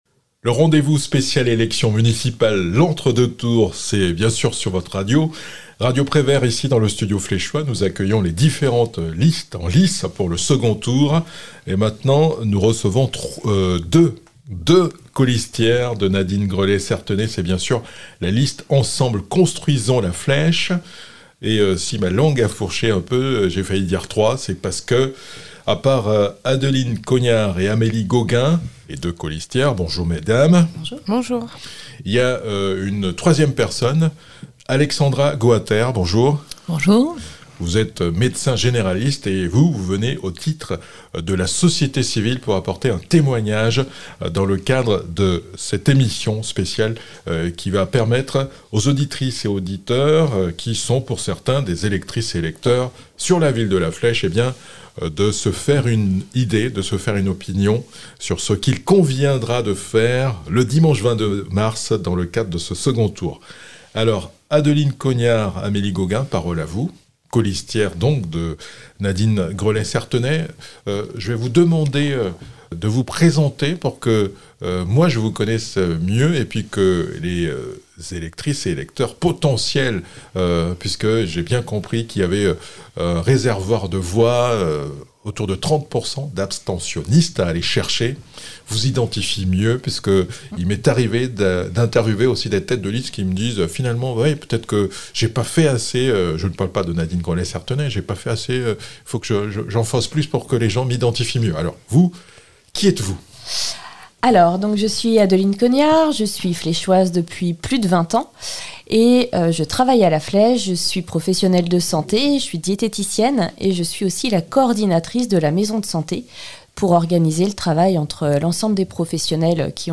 Santé, sport et vie associative : une médecin et deux colistières de Nadine Grelet-Certenais témoignent